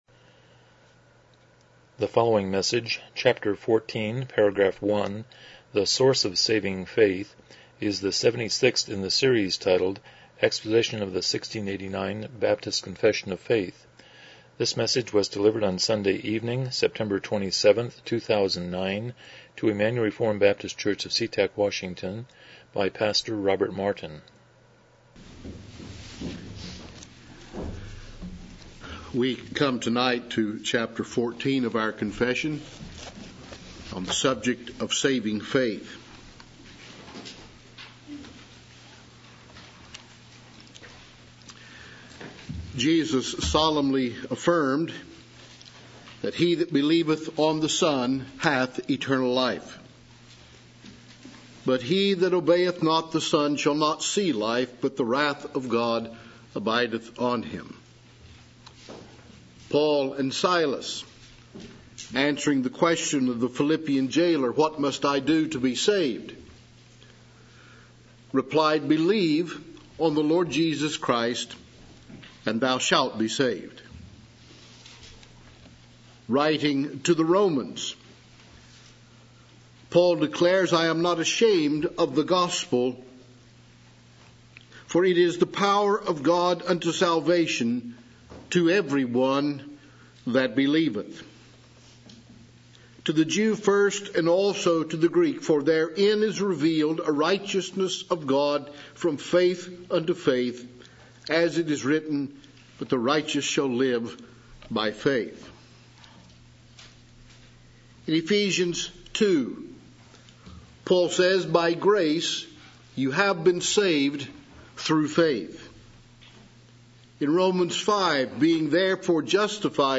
1689 Confession of Faith Service Type: Evening Worship « 93 Romans 8:12-13